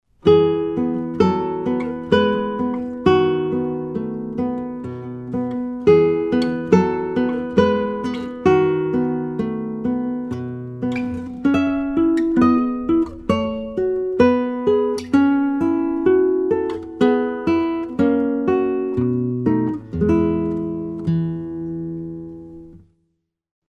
Torres FE - 17 style guitar
European Spruce soundboard, European Maple back & sides-